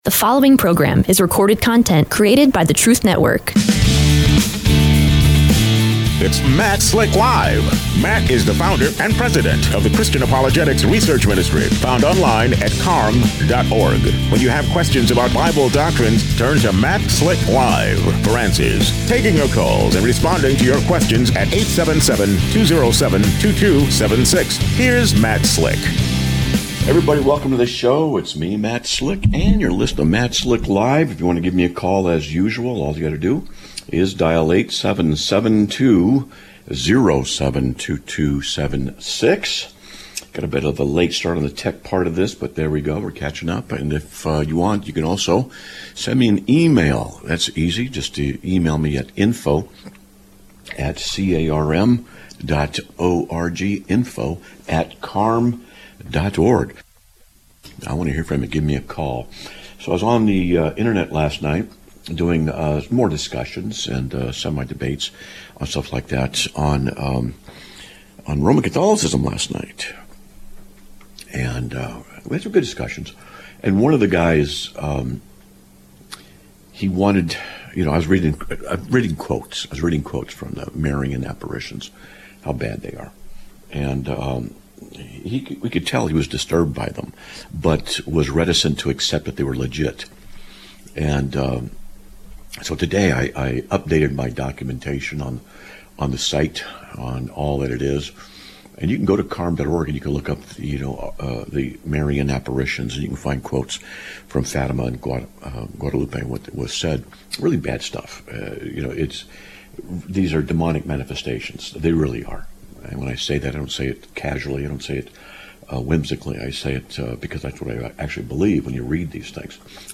Live Broadcast of 10/09/2025
A Caller Inquires About CARM's Three Online Study Courses